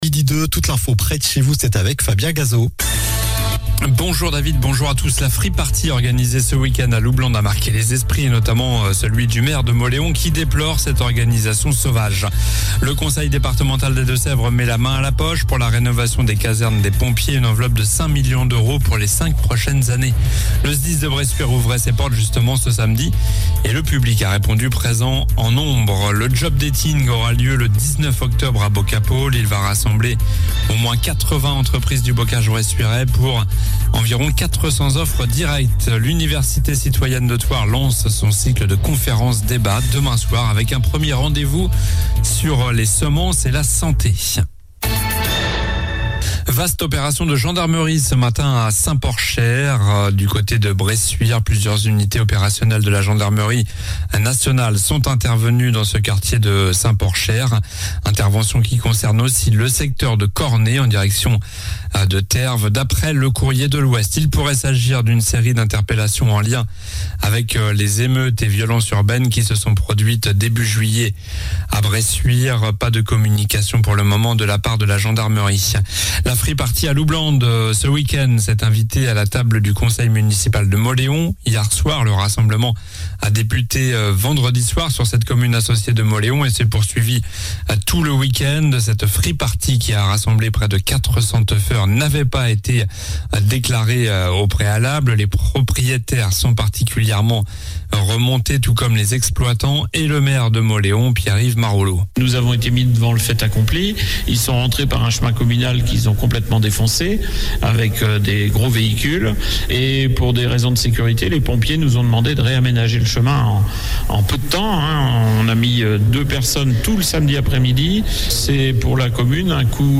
Journal du mardi 26 septembre (midi)